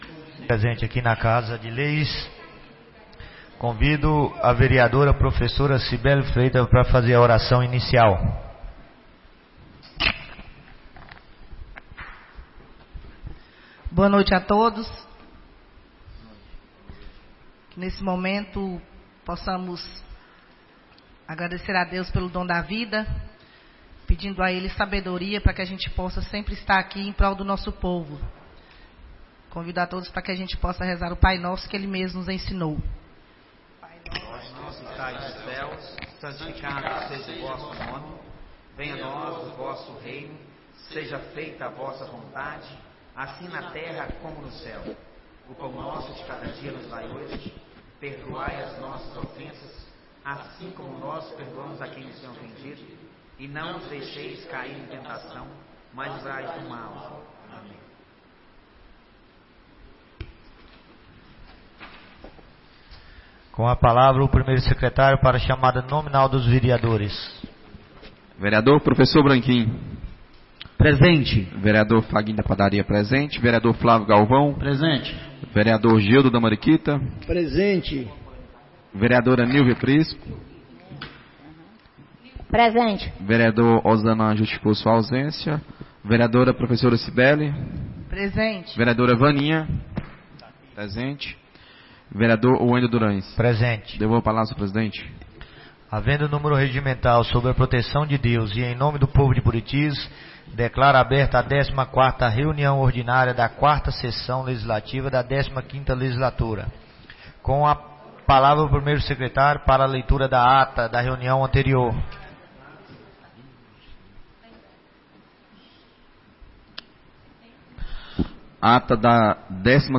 14ª Reunião Ordinária da 4ª Sessão Legislativa da 15ª Legislatura - 06-05-24